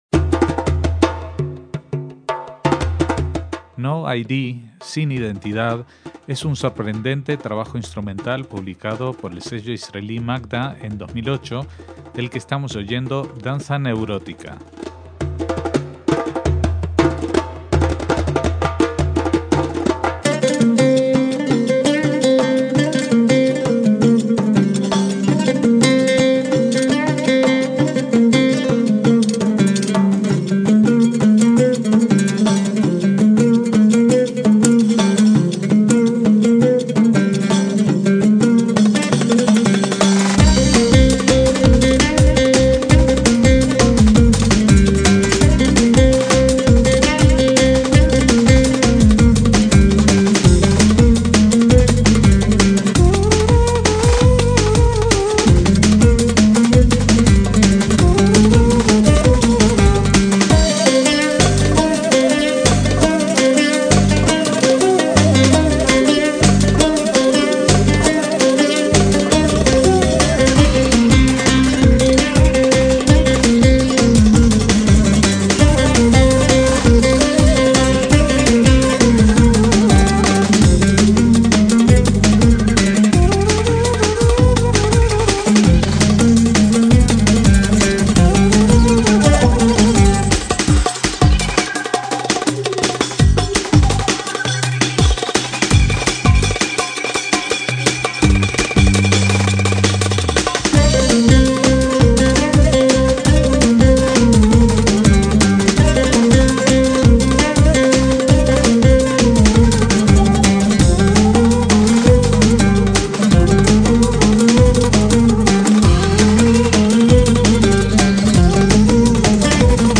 su álbum instrumental de 2008 pasó bastante desapercibido.
laúd árabe, banjo turco y baryton (una especie de trompeta)
flautas